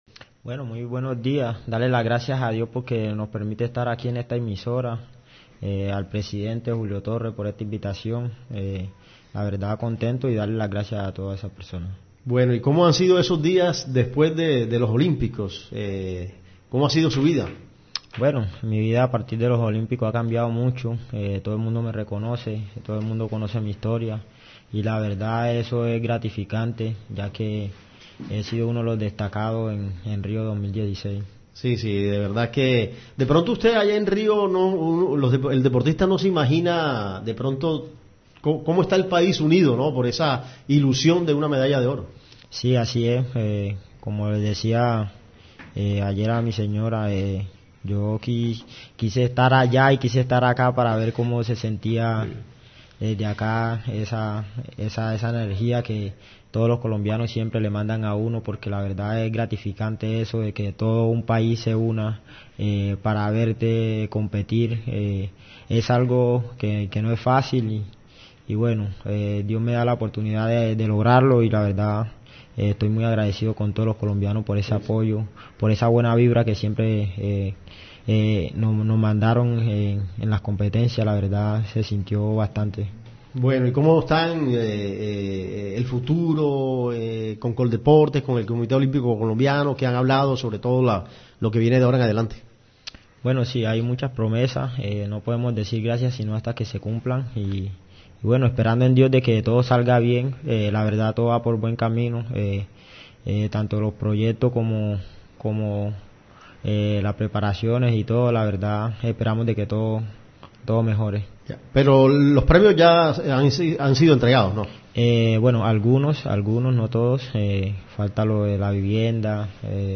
Carismático, sencillo y espontáneo, el púgil se dejó contagiar por la música y fue captado en los estudios de Emisora Atlántico bailando salsa.